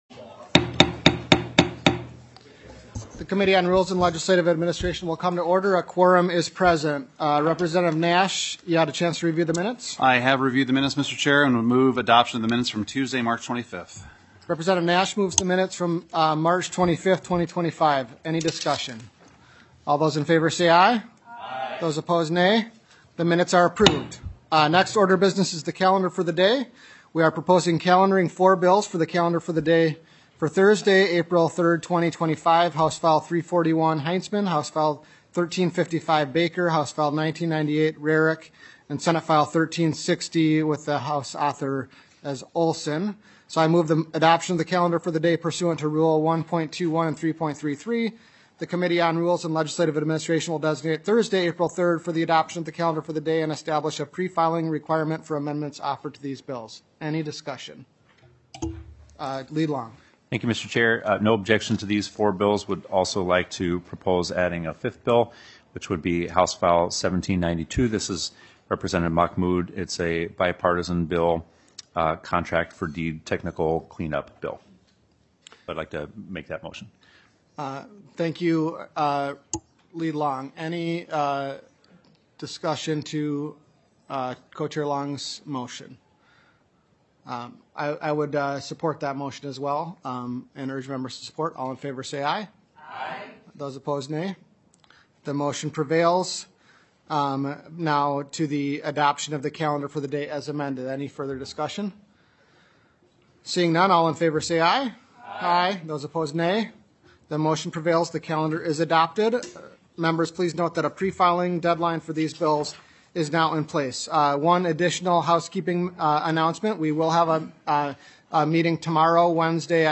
Representative Niska, Co-Chair of the Rules and Legislative Administration Committee, called the meeting to order at 10:05 A.M. on April 1st, 2025, in Room G3 of the State Capitol.